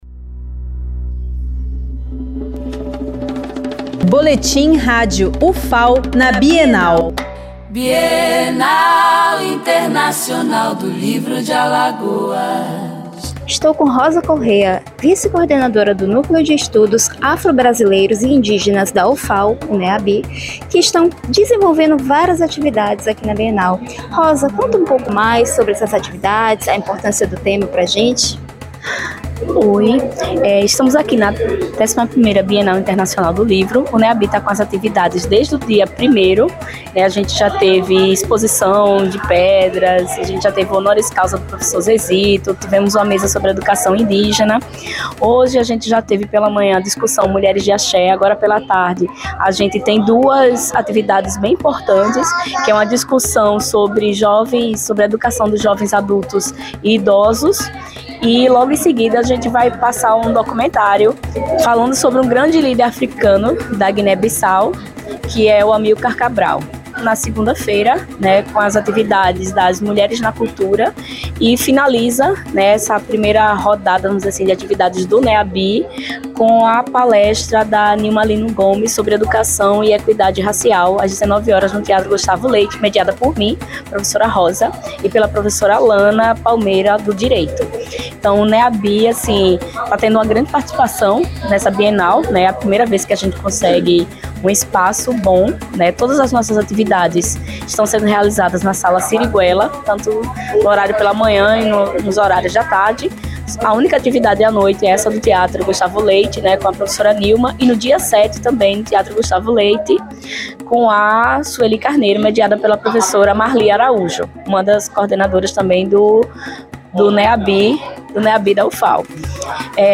11ª Bienal Internacional do Livro de Alagoas, de 31 de outubro a 9 de novembro de 2025